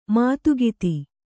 ♪ mātugiti